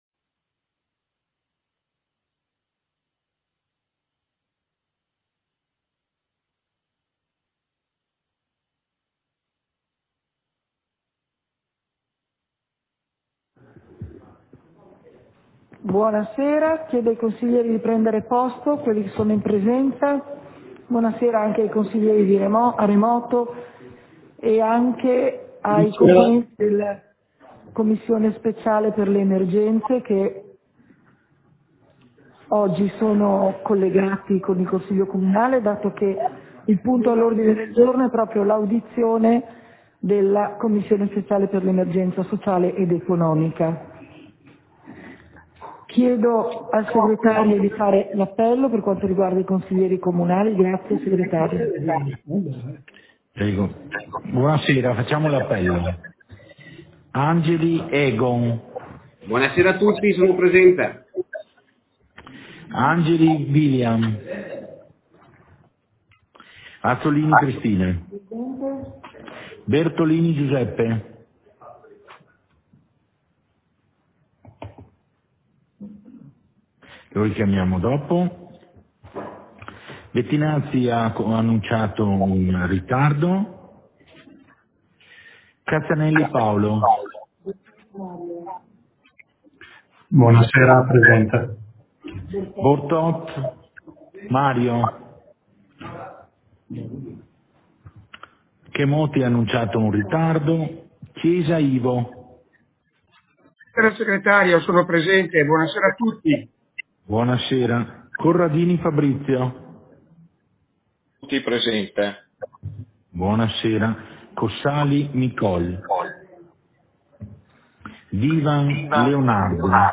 Seduta del consiglio comunale - 18.01.2022